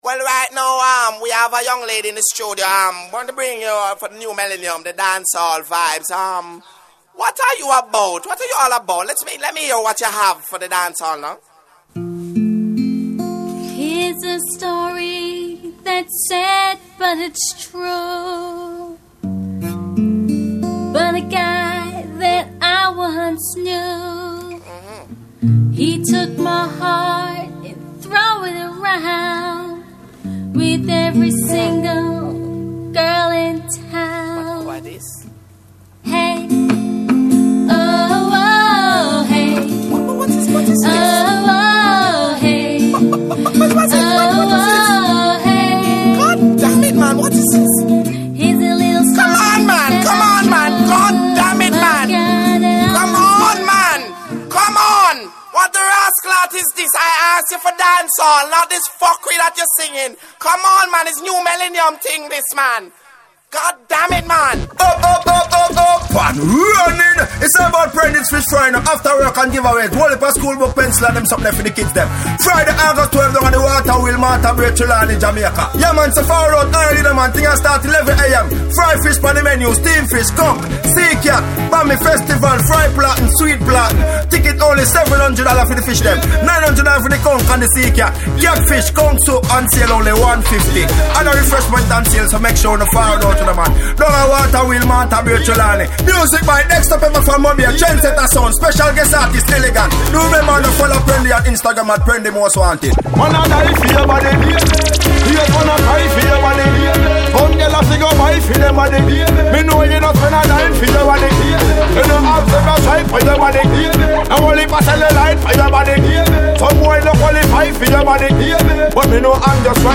PROMOTIONAL STREET MIXTAPE